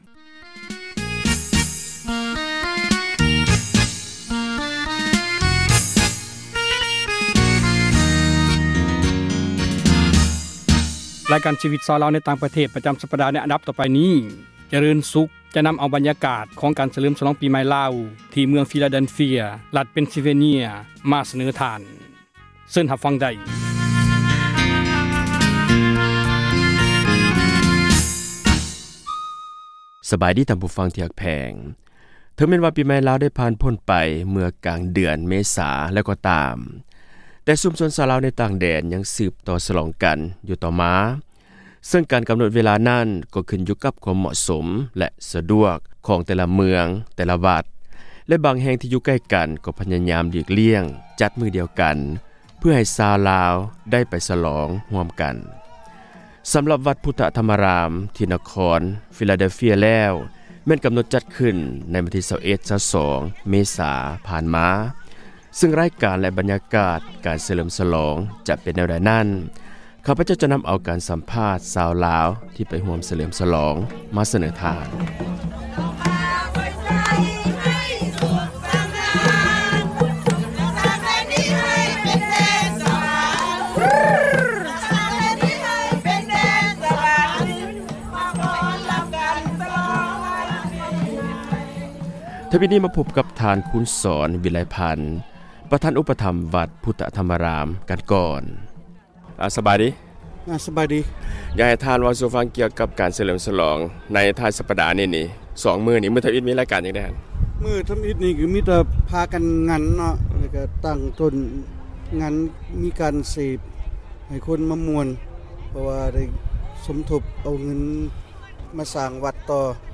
f-lao-new-year-pa ບັນຍາກາດ ການສເລີມສລອງ ປີໃໝ່ລາວ ທີ່ນະຄອນ ຟີລາແດນເຟັຽ ສະຫະຣັດ ອະເມຣິກາ